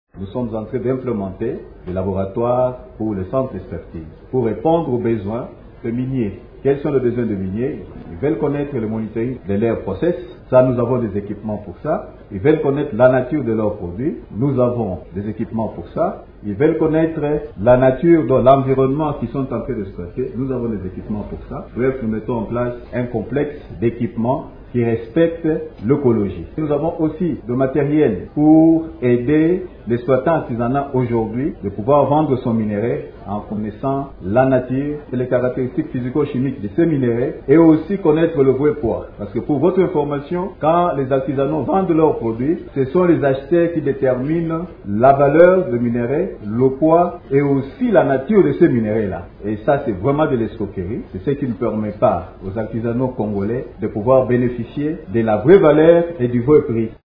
Ce dernier avait lors de son intervention à la Semaine minière de la RDC, organisée à Lubumbashi, expliqué l’importance de ce laboratoire, notamment pour les entreprises minières et pour les exploitants artisanaux :